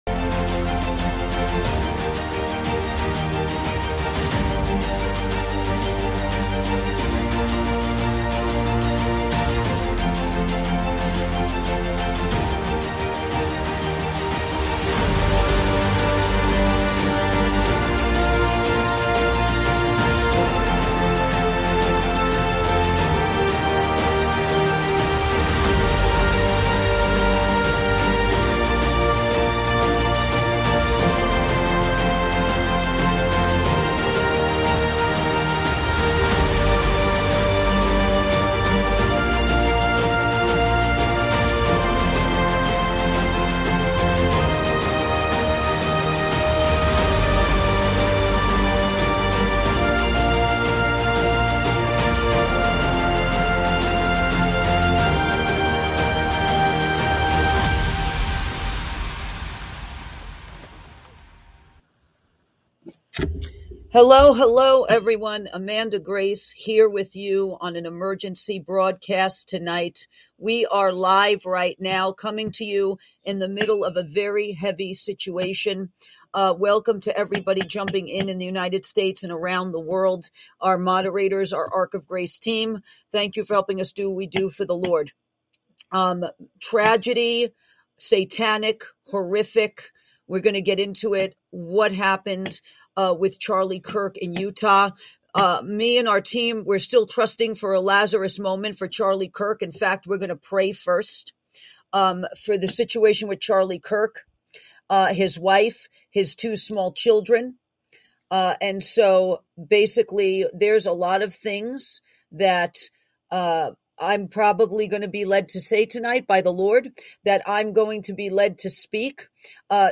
High Alert: Emergency Live, Charlie Kirk, 911